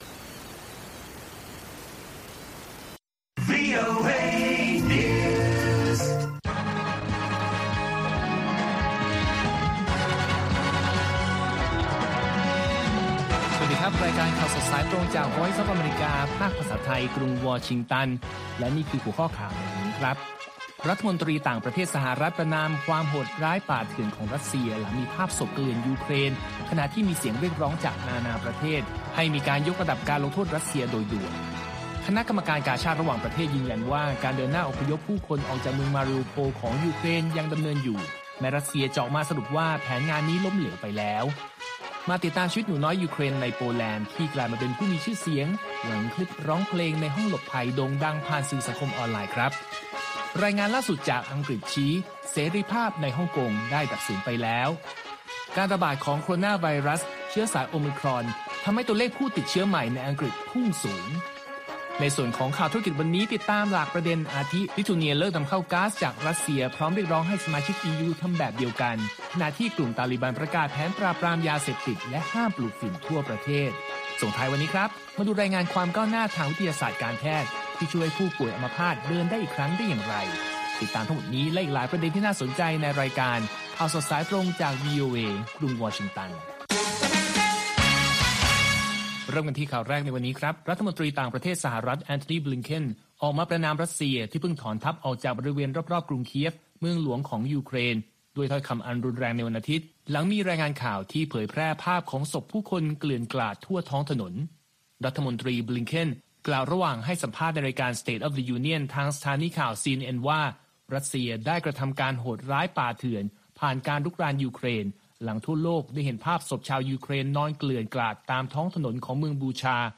ข่าวสดสายตรงจากวีโอเอ ภาคภาษาไทย 6:30 – 7:00 น. ประจำวันจันทร์ที่ 4 เมษายน 2565 ตามเวลาในประเทศไทย